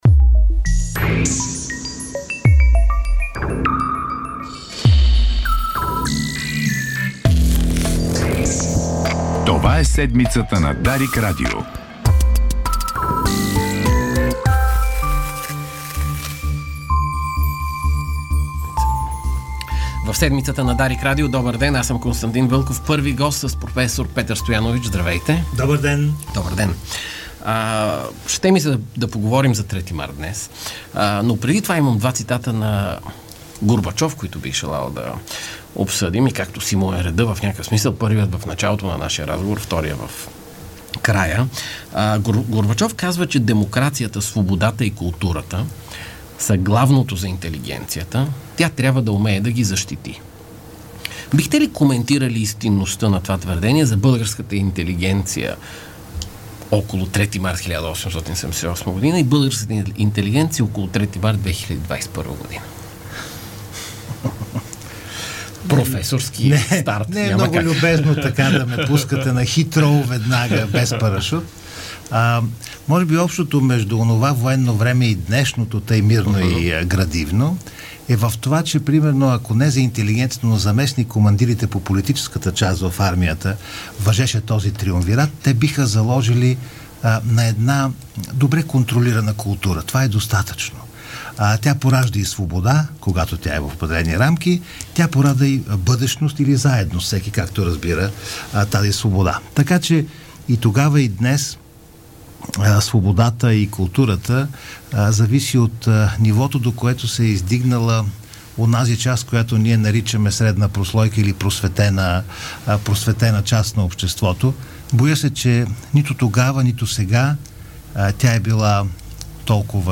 Разговор за свободата и културата, за миналото и настоящето, за трети март.